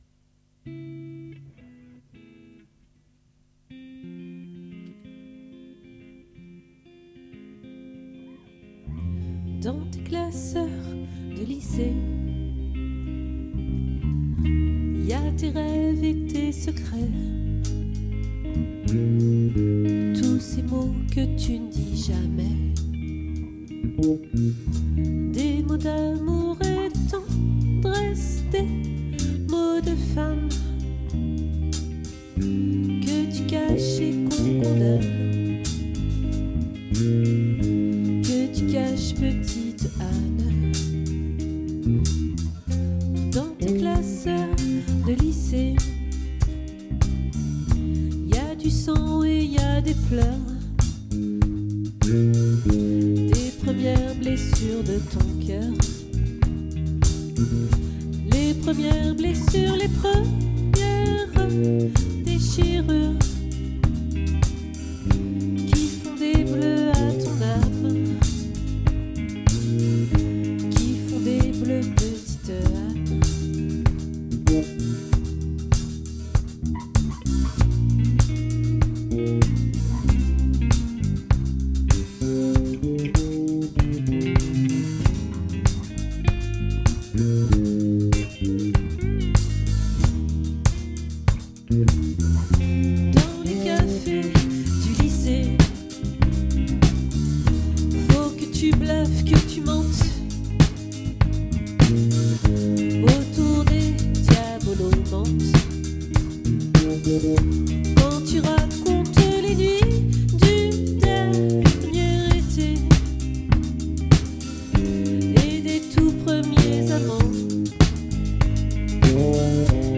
🏠 Accueil Repetitions Records_2024_02_20_OLVRE